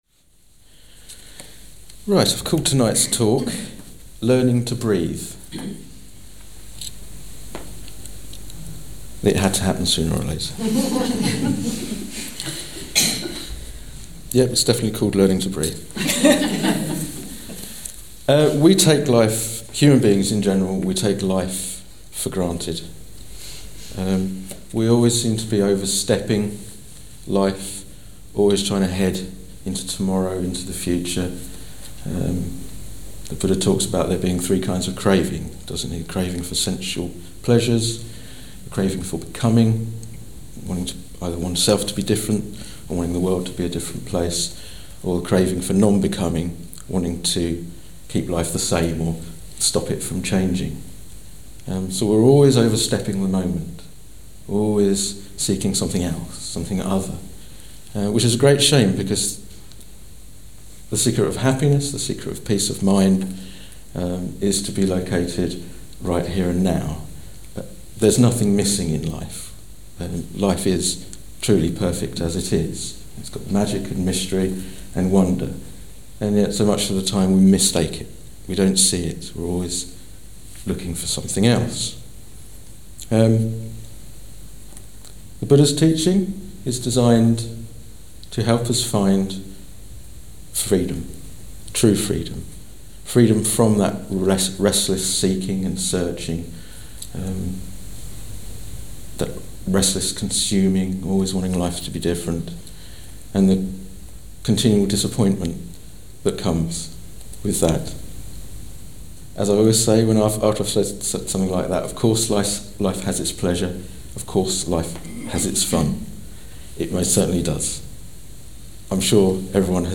Countless talks on all aspects of the Buddha's teaching have been given at the Centre over the years.